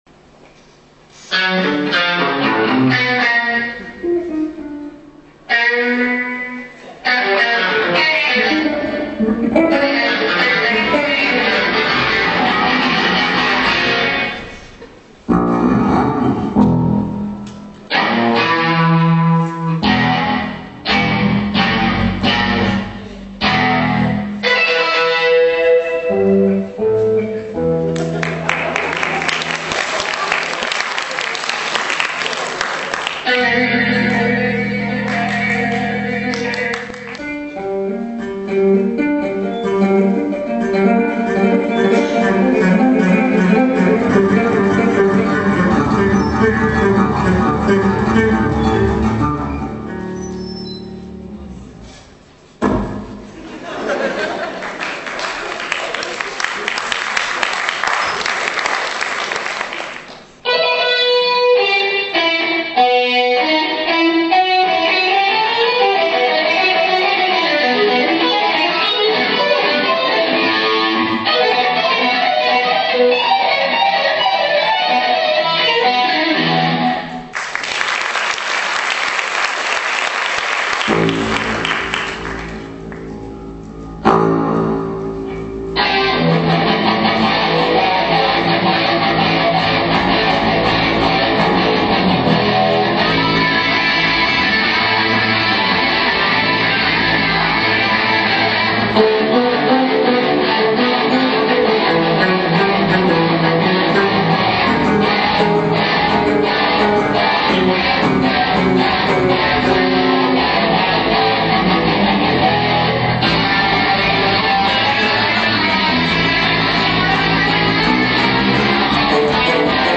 Это запись с концерта.